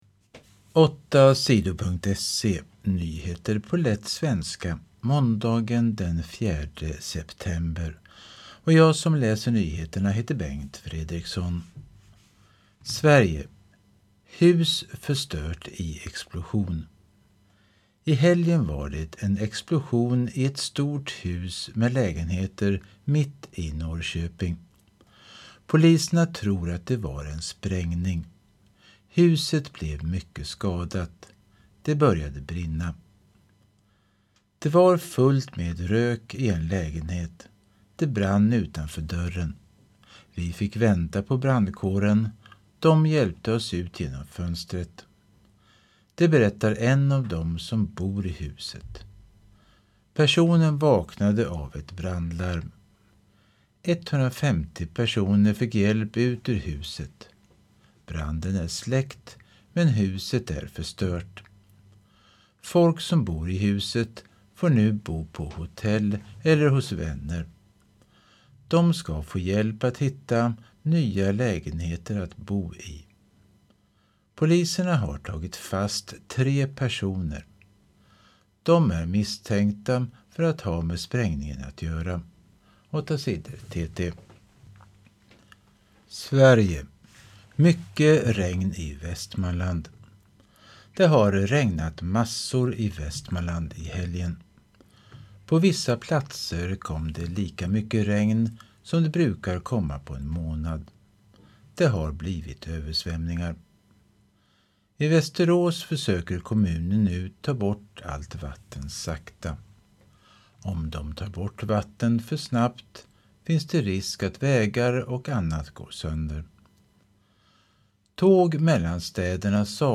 Lyssnar på nyheter från 2023-09-04.